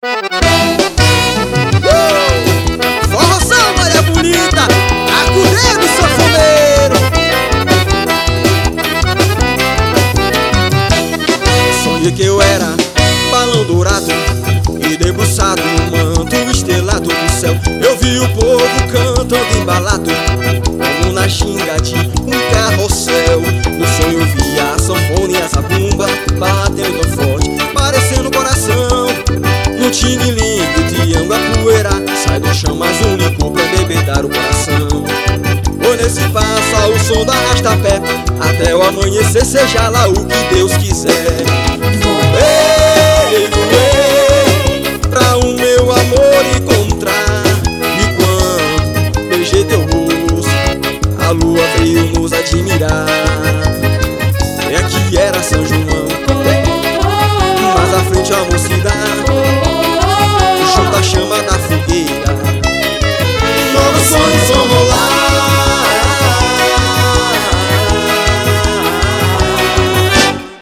Ao vivo em Fortaleza.